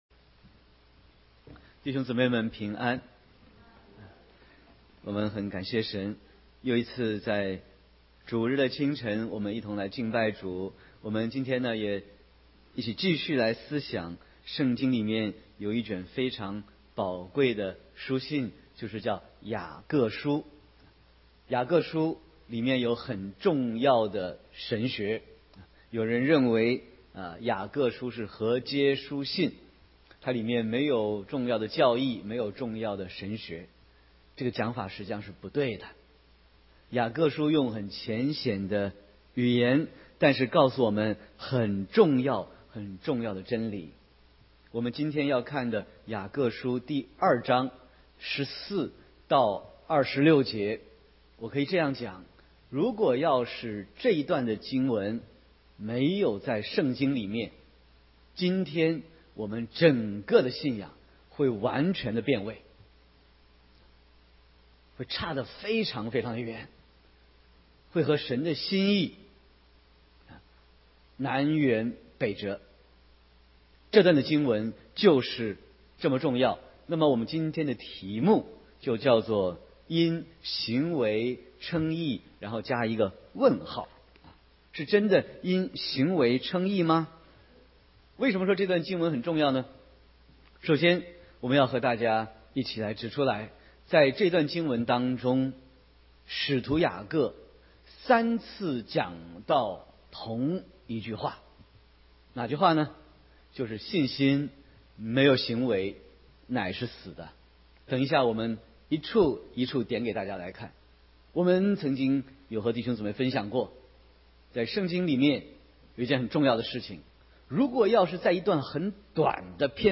Sermon 4/22/2018